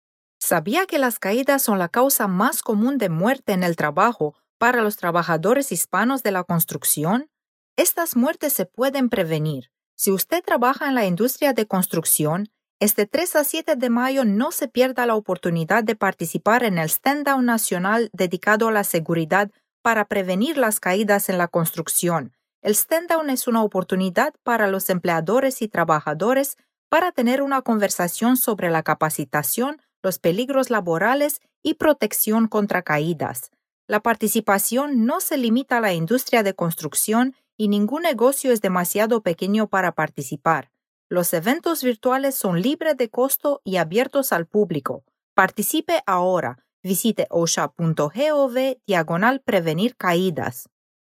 public service announcement.
FSD Spanish PSA 1.mp3